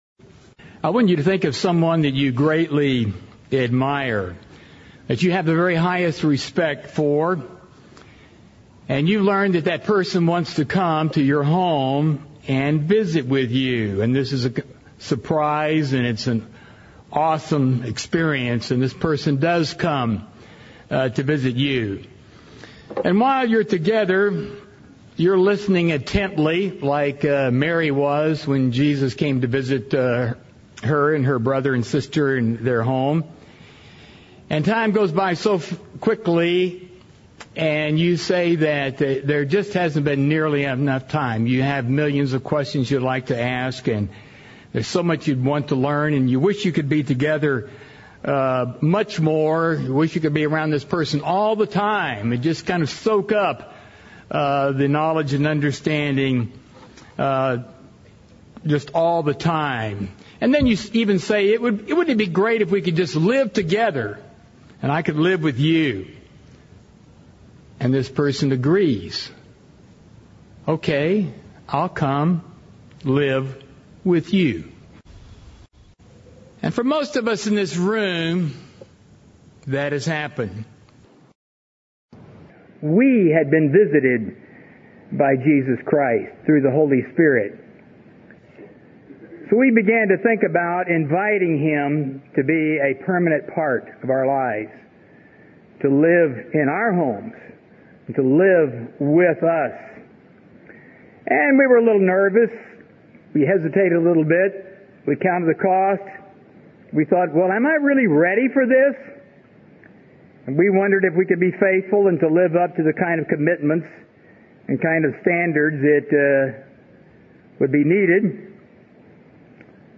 This message was given on the Feast of Pentecost.
Given in Burlington, WA
UCG Sermon Studying the bible?